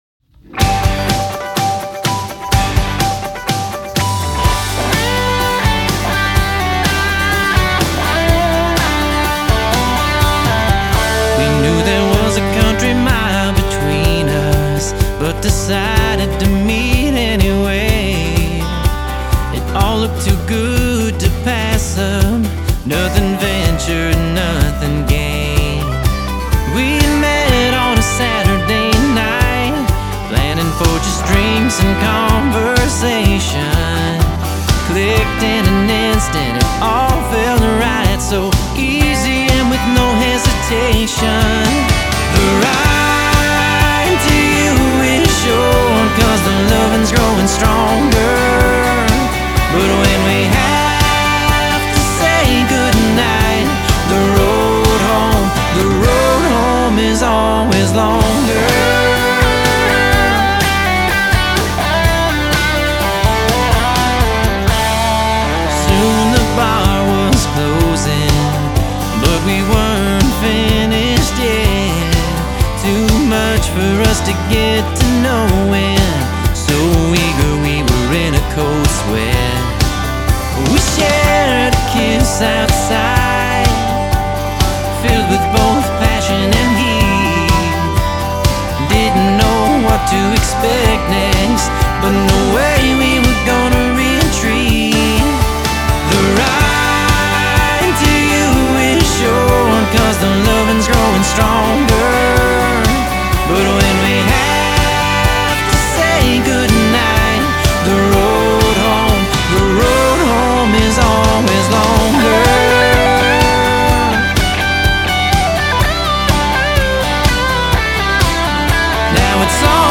"The Road Home Is Always Longer" (country)